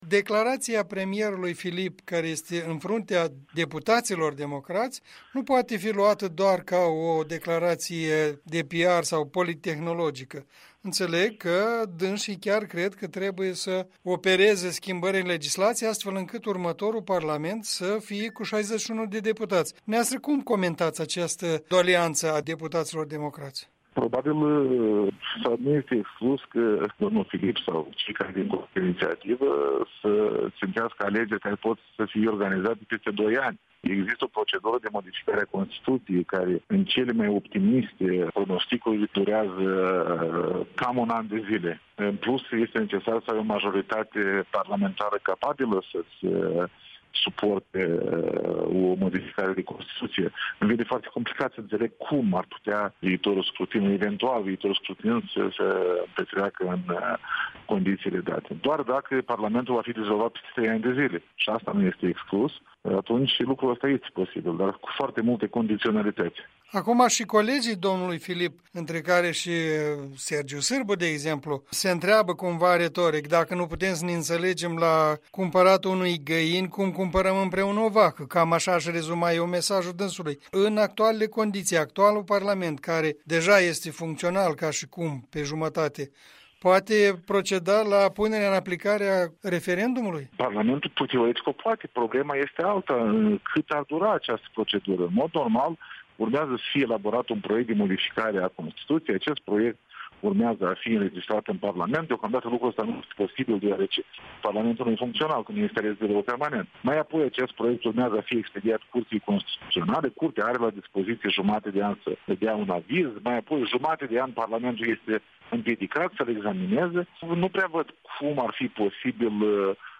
Alexandru Tănase, fost președinte al Curții Constituționale
Interviu cu Alexandru Tănase